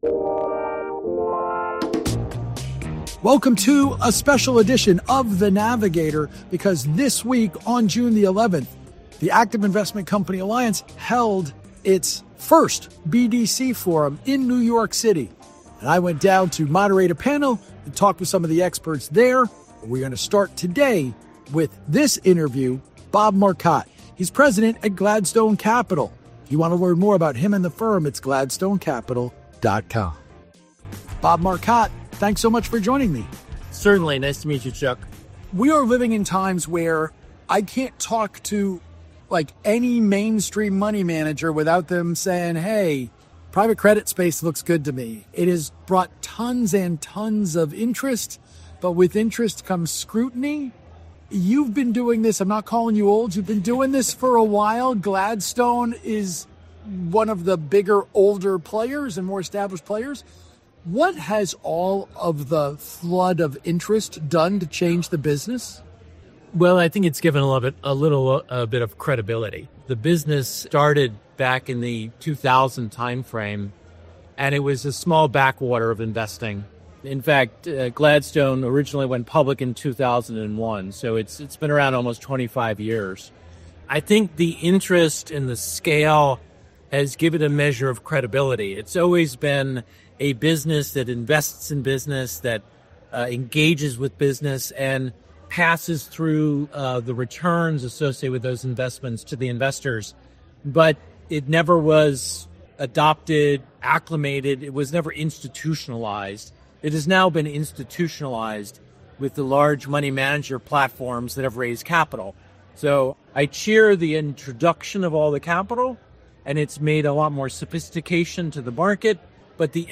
In an interview at the Active Investment Company Alliance BDC Forum in New York City